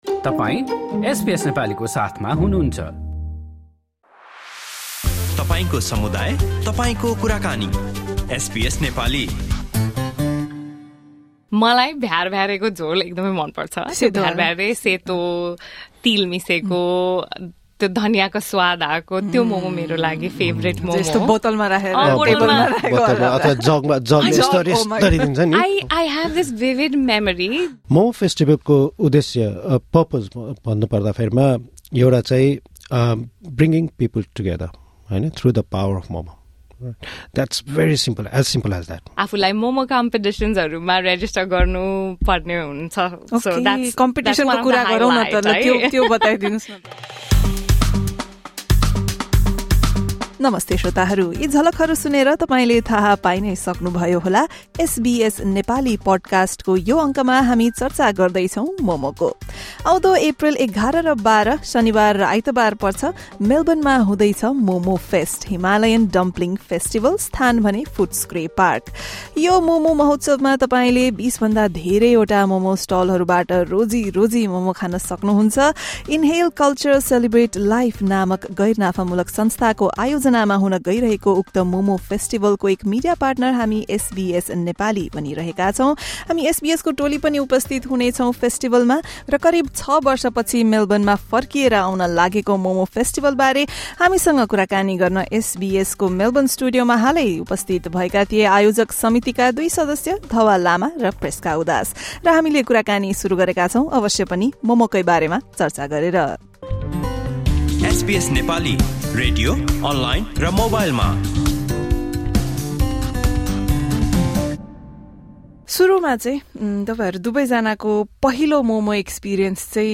Subscribe to the SBS Nepali podcast here .